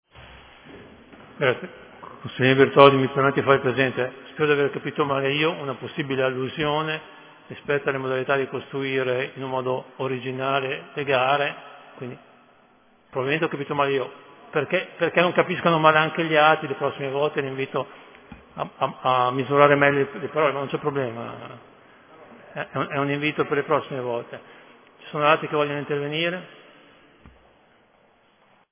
Seduta del 02/07/2020. SU INTERVENTO DEL CONSIGLIERE BERTOLDI